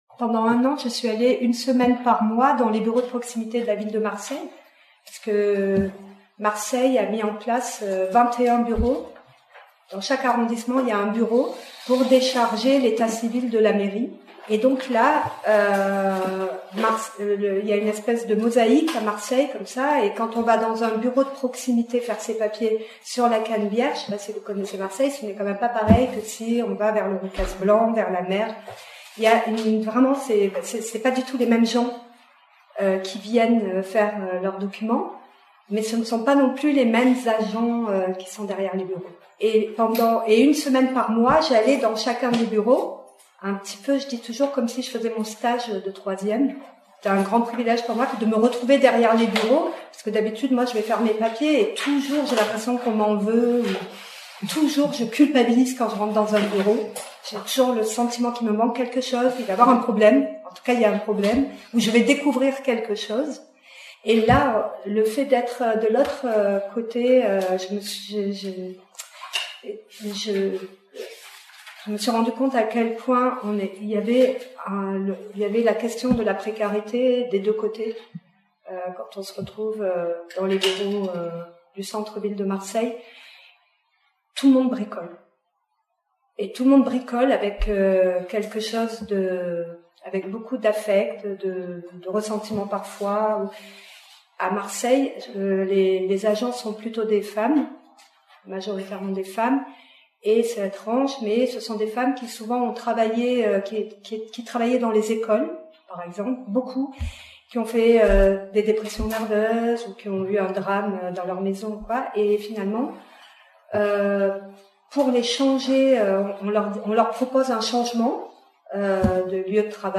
À Marseille, d’un « bureau de proximité » à l’autre, les dialogues se succèdent en même temps que s’affirme un témoignage tout en nuances des réalités dures et pourtant banales de ceux qui s’emploient tout simplement à être en règle. Face à eux, des employés souvent démunis, qui appliquent comme ils le peuvent des protocoles administratifs rigides laissant peu de place aux traitements spécifiques qu’appelleraient pourtant les situations de chacun.